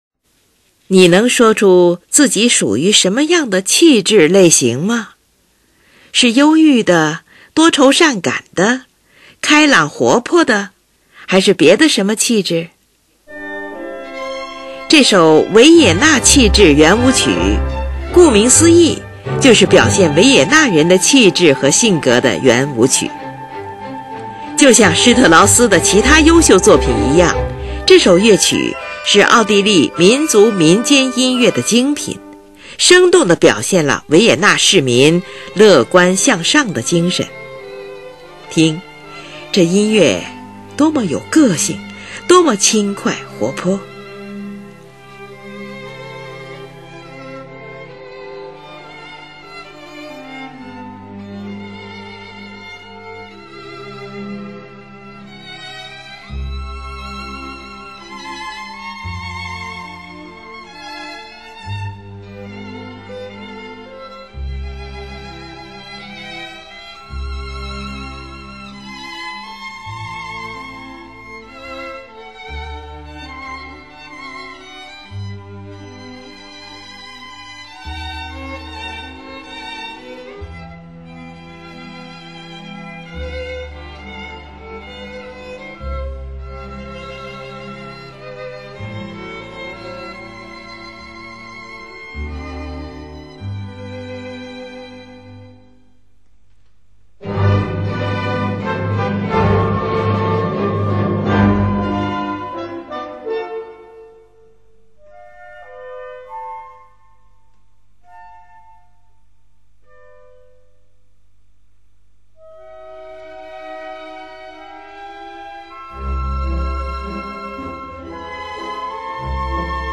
听！这音乐多么有个性，多么的轻快活泼！
这首乐曲由7个不同的圆舞曲组成。